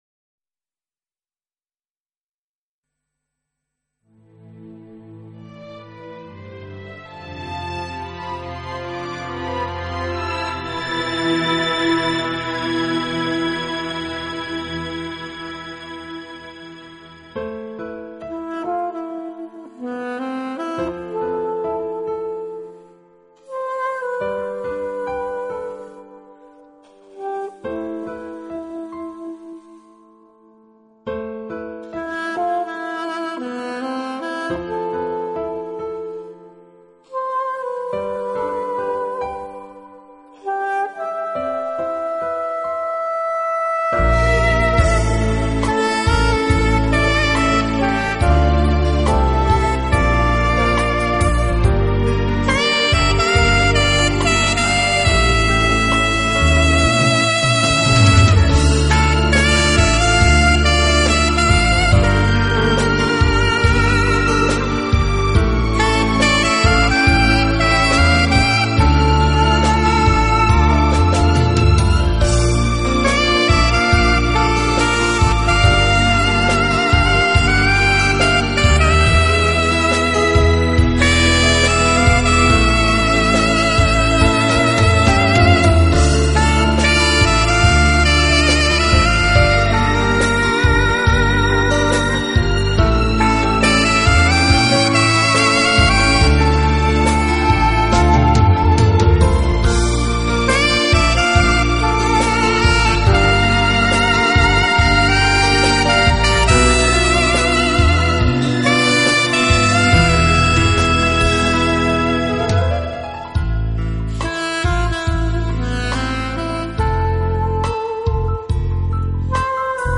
我的爱因你而存在缠绵的萨克斯乐曲，演绎浪漫的情人物语，
这丝丝情意像恋人般彼此依偎，轻轻地，静静地直到永远！